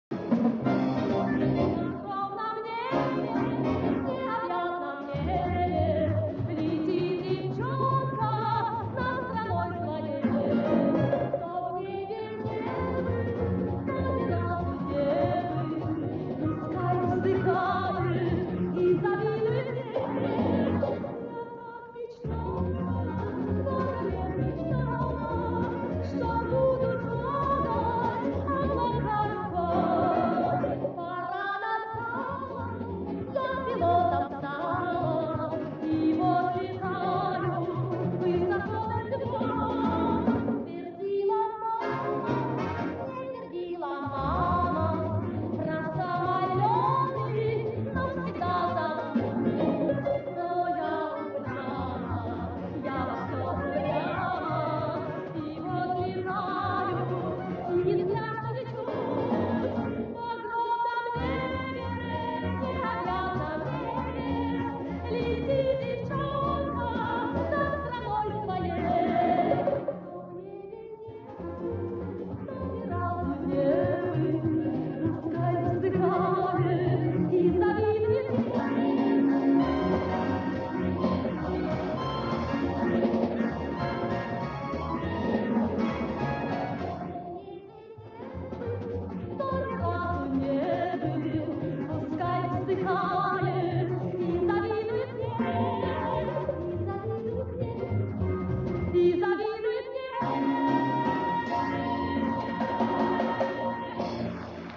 Похоже эти записи с радиоприемника 3-го класса.